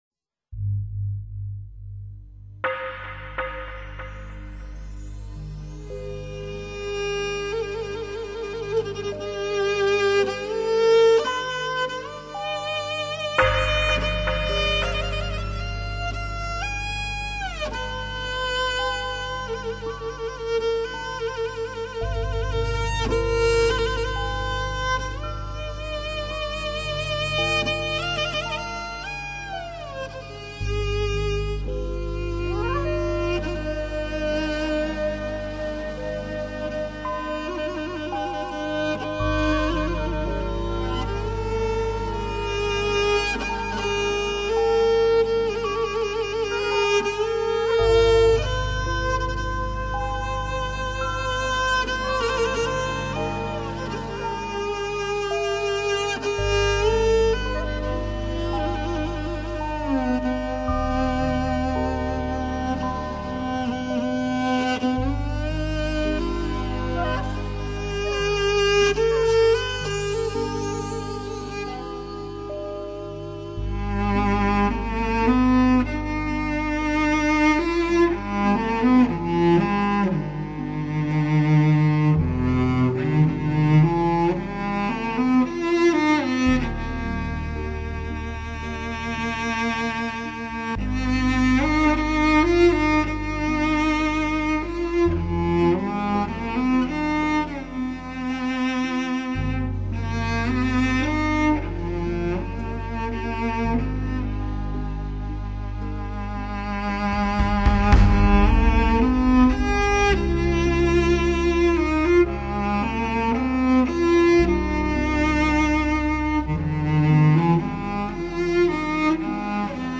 大提琴